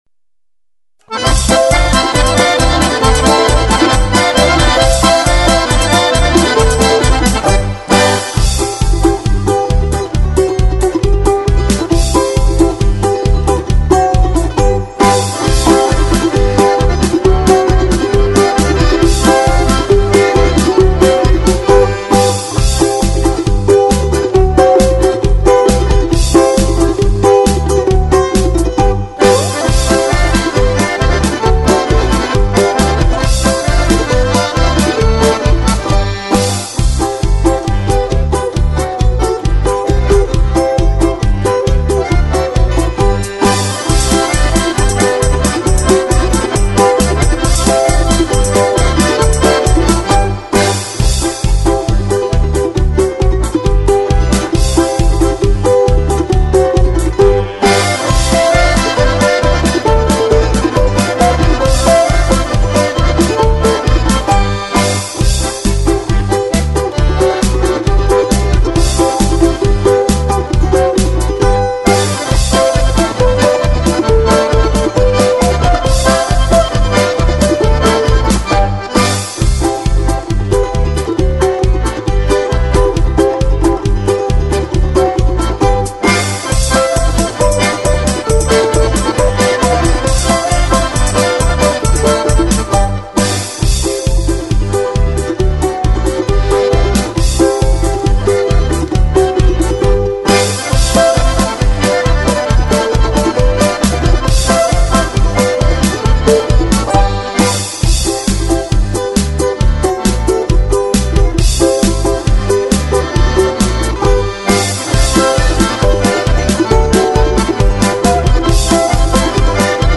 Музыка из шестнадцати куплетов